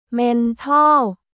เมนทอล　メントーン